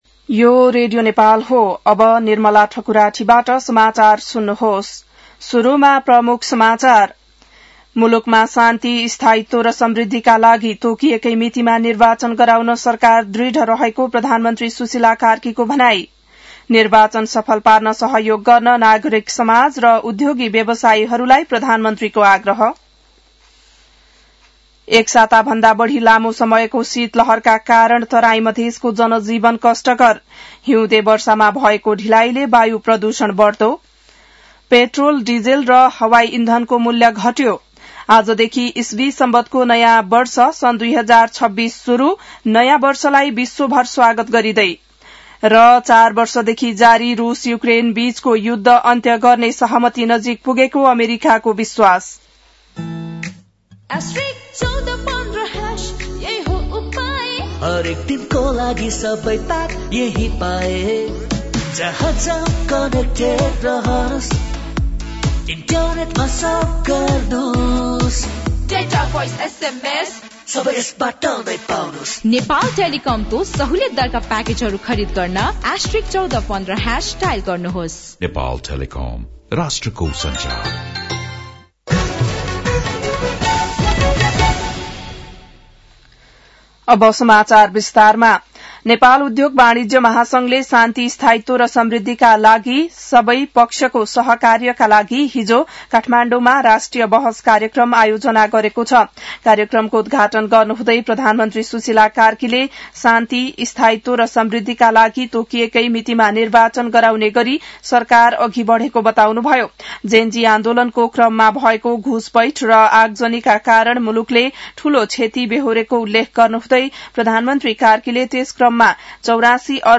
बिहान ७ बजेको नेपाली समाचार : १७ पुष , २०८२